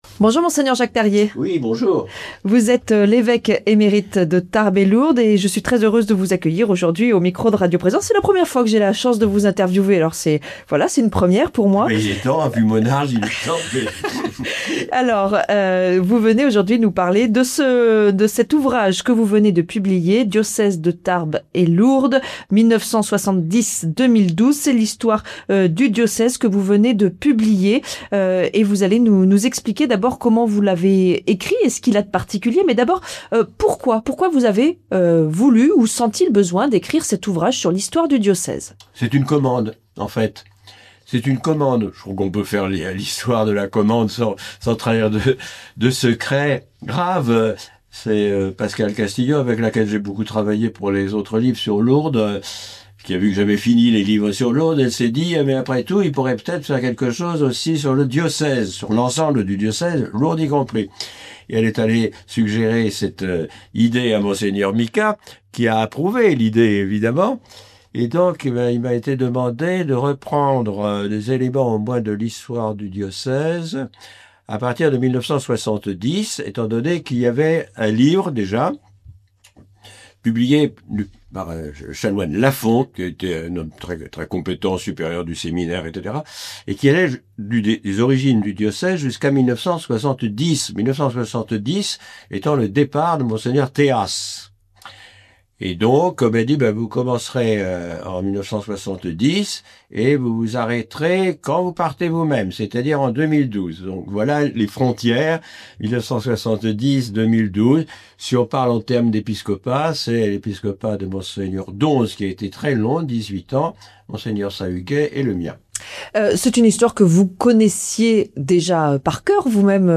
Diocèse de Tarbes et Lourdes 1970-2012, c'est le titre de l'ouvrage que Mgr Jacques Perrier nous présente